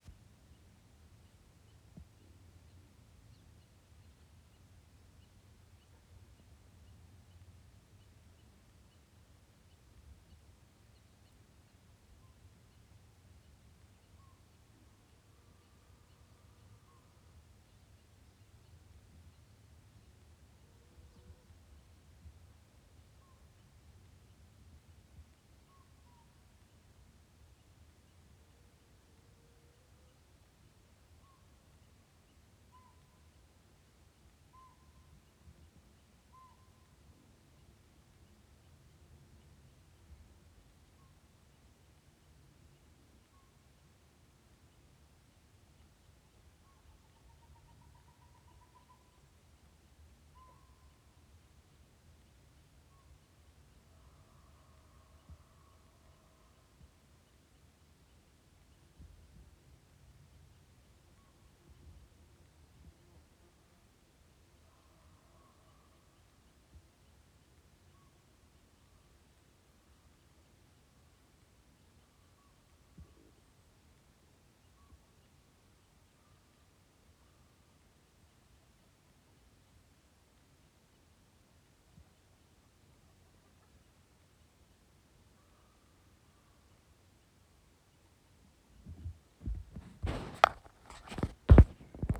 Pygmy owl 2